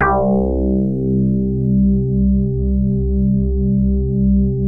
JAZZ HARD C1.wav